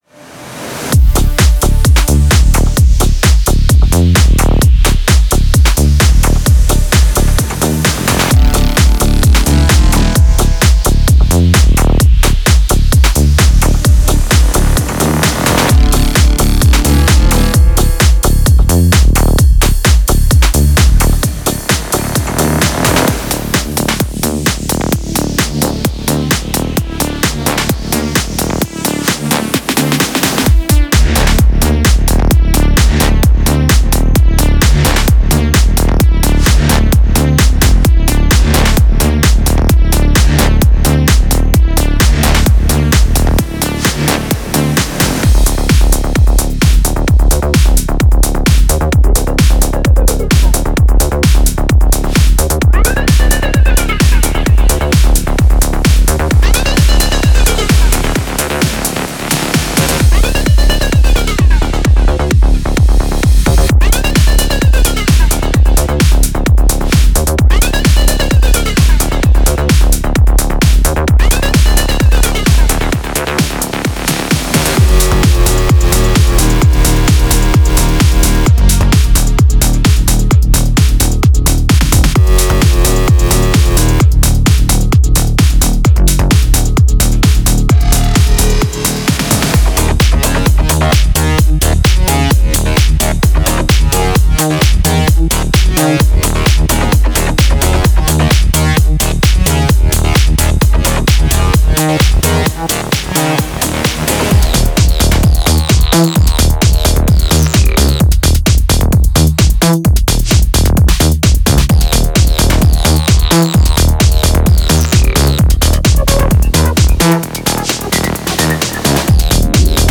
House Tech House
Includes loops and one-shots, ready for immediate use.
Upgrade your production sessions with sounds that sound like tech house on steroids.